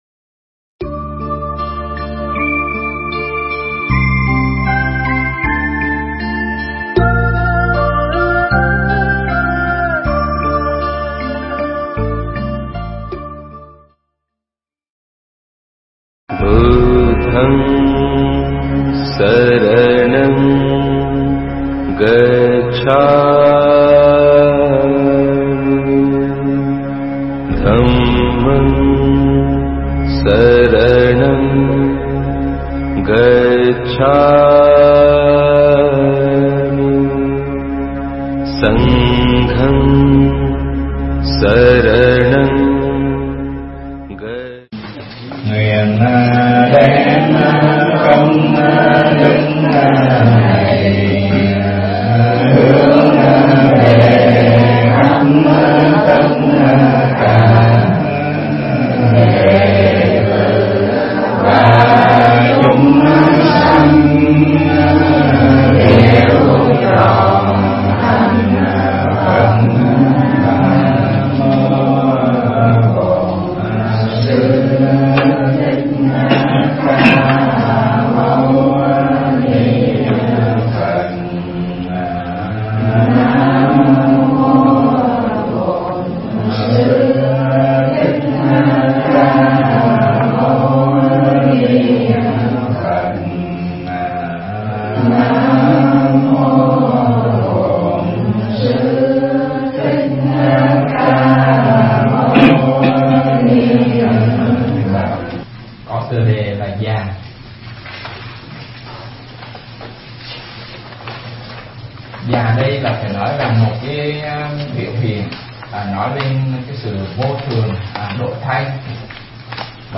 Mp3 Thuyết pháp Kinh Pháp Cú Phẩm Già